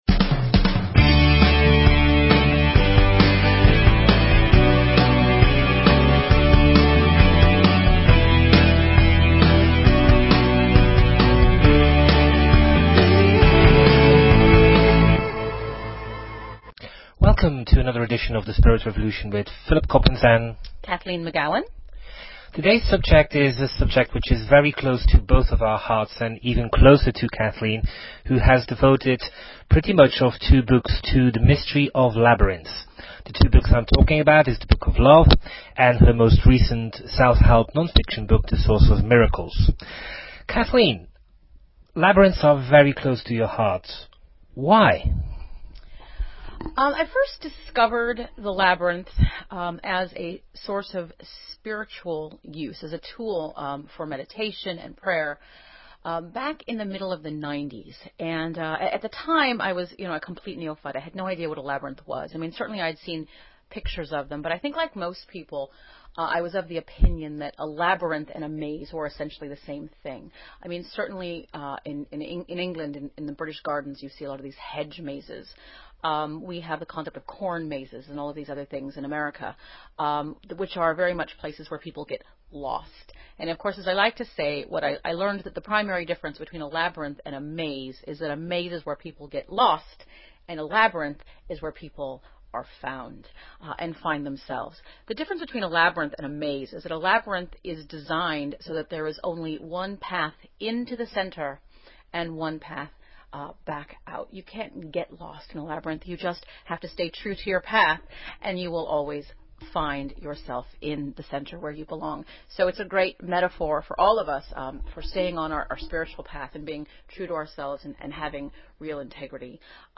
Talk Show Episode, Audio Podcast, The_Spirit_Revolution and Courtesy of BBS Radio on , show guests , about , categorized as
The Spirit Revolution is a weekly one hour radio show, in which Kathleen McGowan and Philip Coppens serve up a riveting cocktail of news, opinion and interviews with leaders in the fields of alternative science, revisionist history and transformational self-help.
The labyrinth as a walking meditation and prayer practice is gaining popularity worldwide, and yet it has a controversial history which experts cannot agree upon. We discuss the histories as Kathleen reads an excerpt from her bestselling novel on the subject, The Book of Love, and contribute to the controversies!